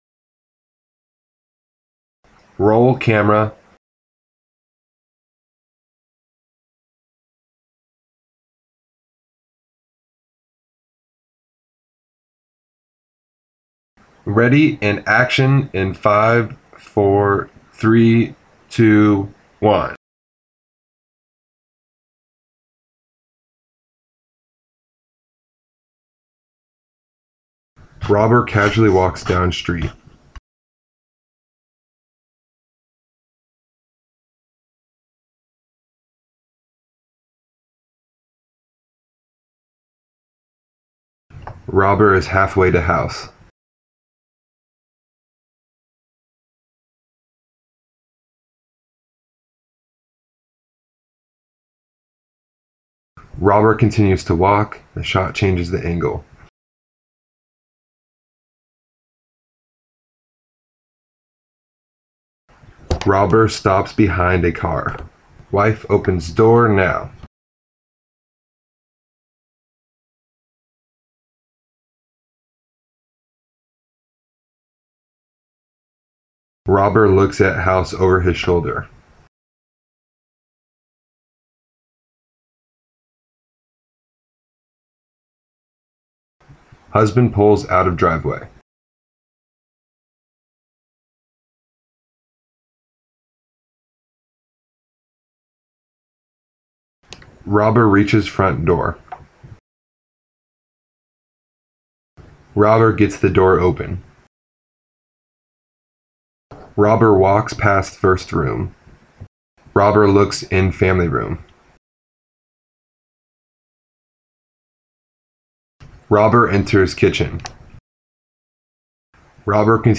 Anyway, I read aloud the actions of the characters.
Here’s both clips of me giving directions for the actors while we shot. I made a different one for each angle.
Split-Life-SATO-48-Voice-Planning_Robber.mp3